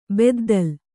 ♪ beddal